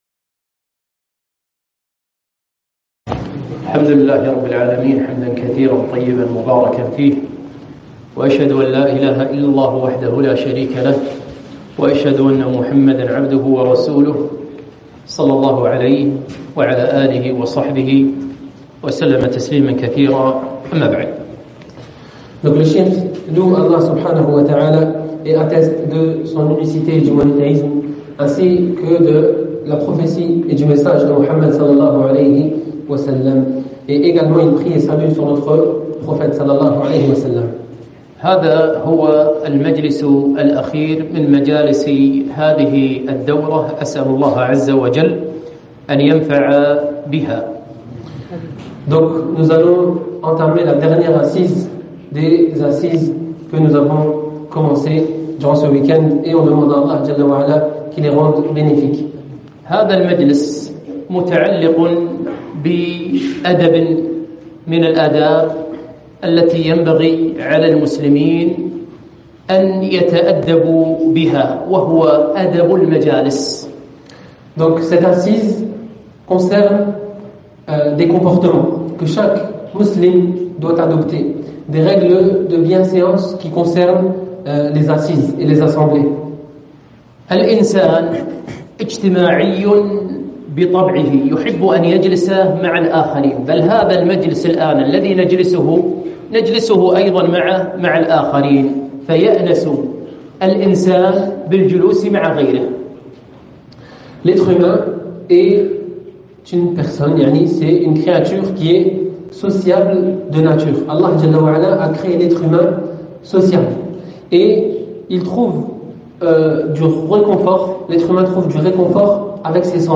محاضرة - آداب المجالس (مترجمة للفرنسية)